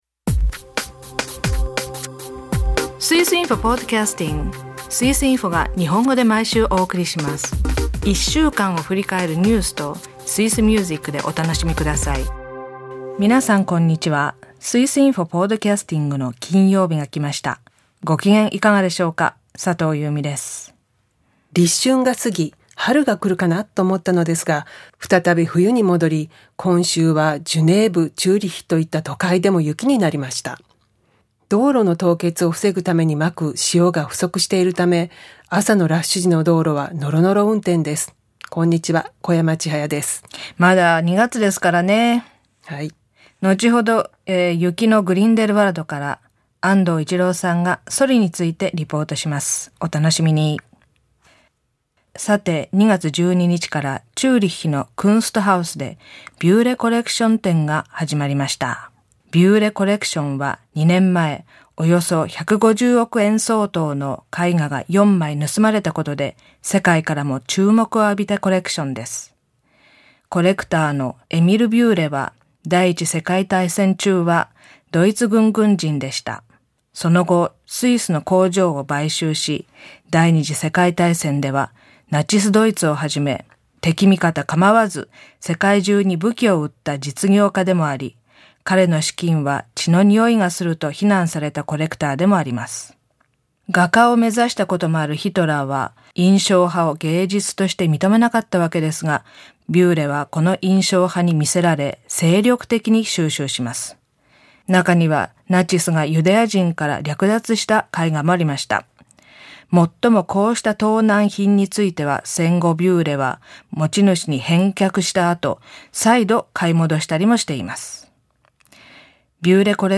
グリンデルワルトの日本語観光案内所に電話インタビュー。音楽はテレビ司会者のロックアルバムから。12回目の朗読「ブレーカーの自伝」では何か変化が…。